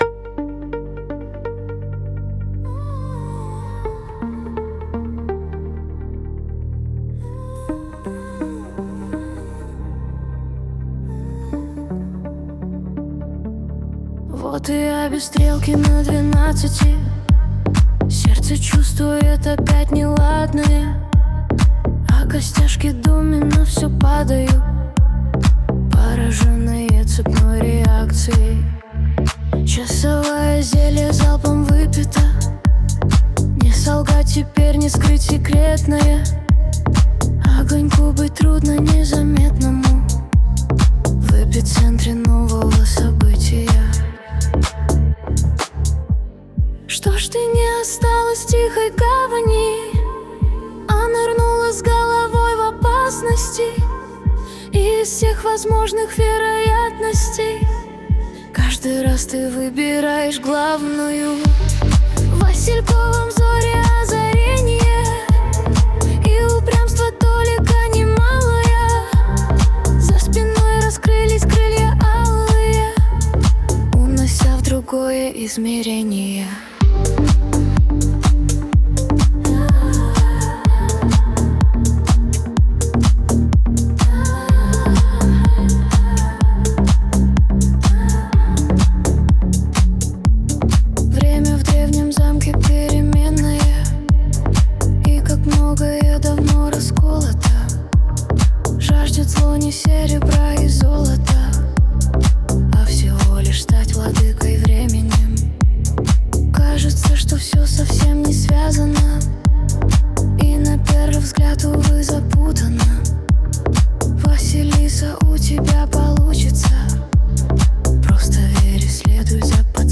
Стихи мои, коряво поет ИИ
Тут, конечно, ИИ схалтурила(в бесплатных версиях она так и делает, ставя где непопадя акценты и ударения), но на безрыбье сами понимаете 🤣🤣🤣
Стихи воспринимаются гораздо глубже, потому что отлично подобрана мелодия.